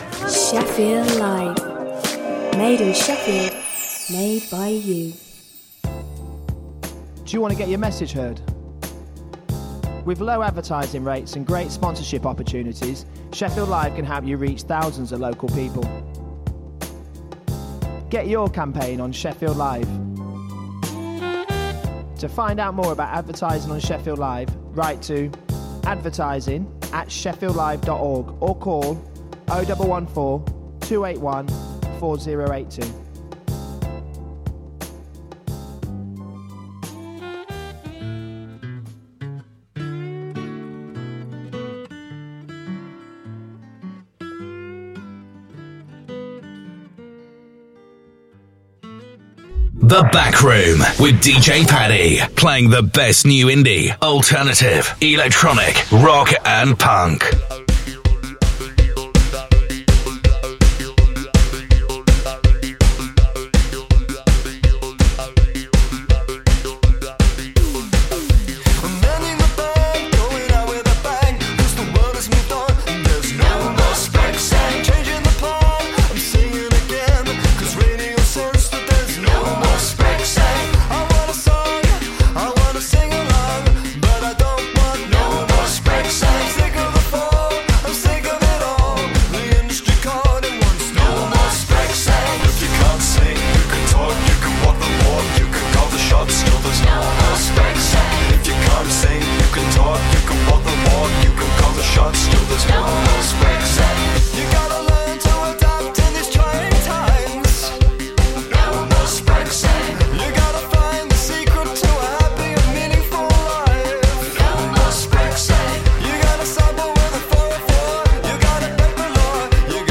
playing the best in new indie , electro, EDM, and punk